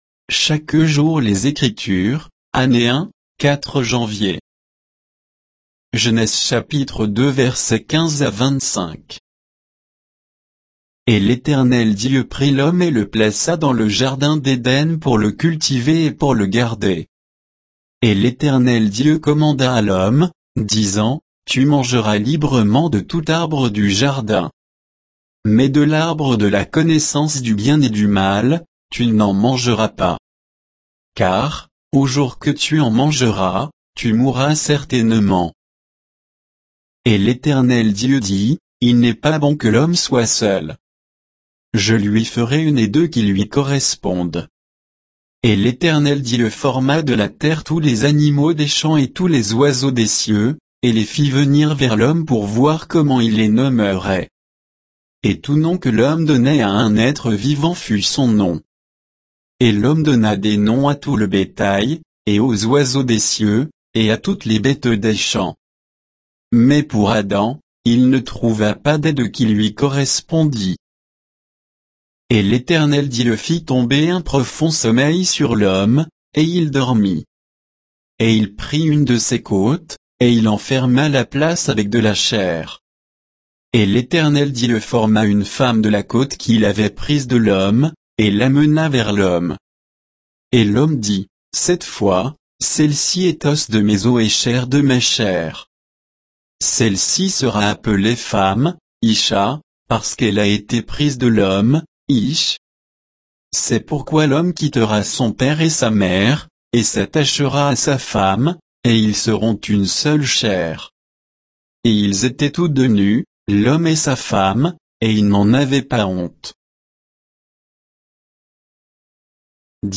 Méditation quoditienne de Chaque jour les Écritures sur Genèse 2, 15 à 25